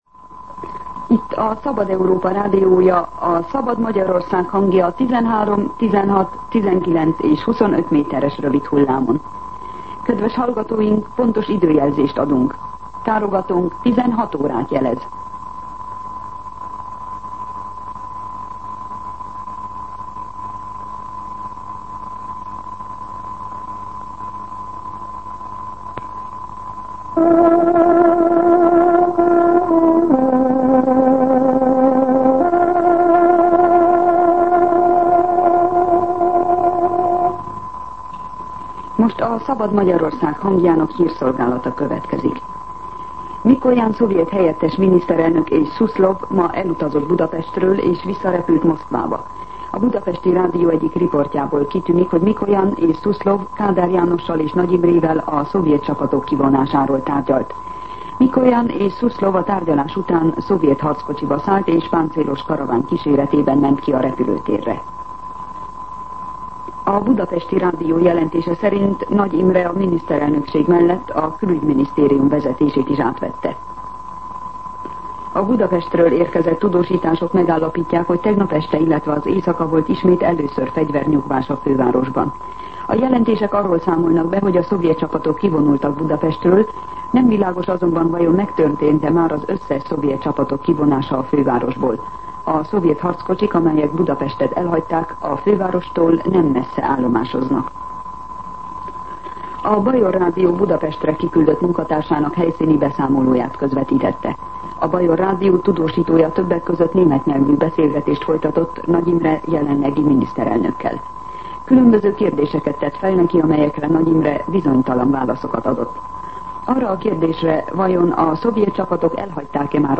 16:00 óra. Hírszolgálat